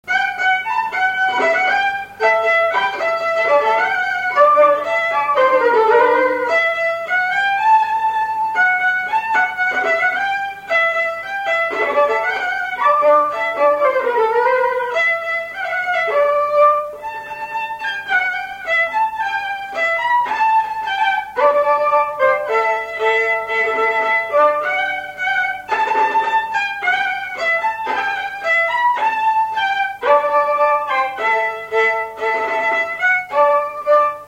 Note polka
Résumé instrumental
Catégorie Pièce musicale inédite